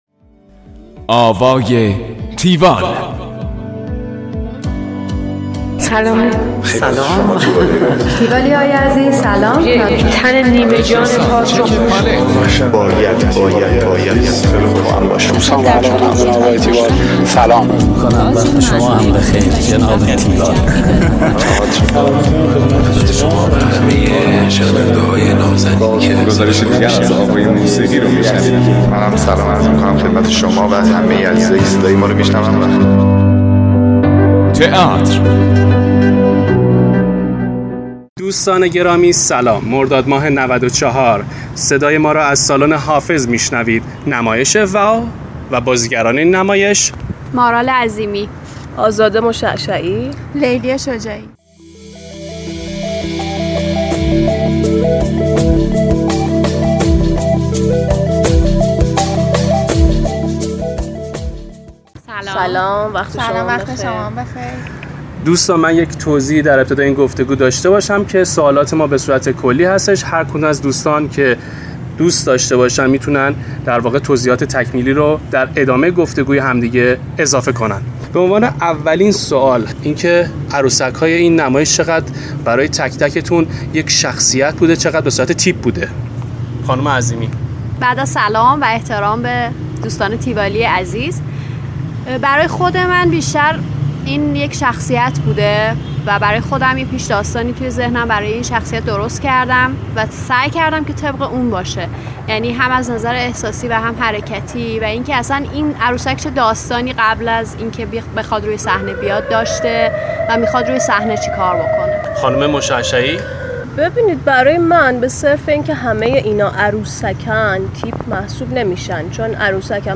گفتگوی تیوال با بازیگران واو